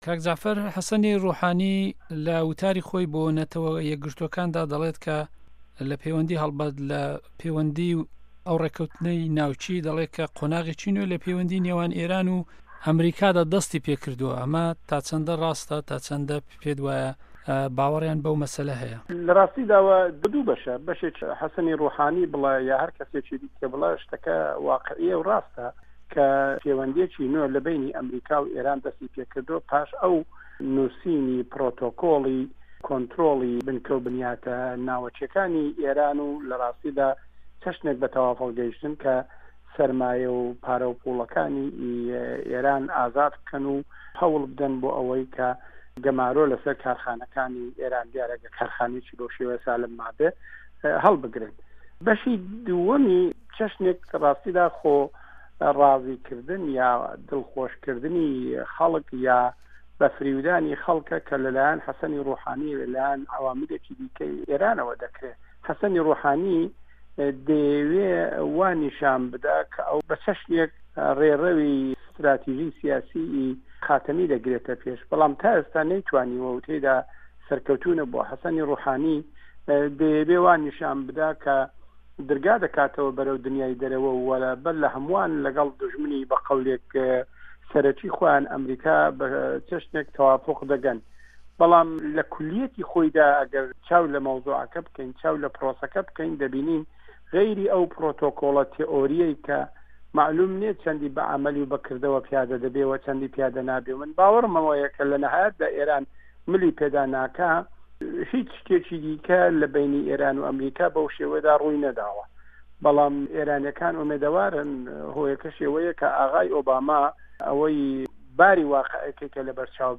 ئێران - گفتوگۆکان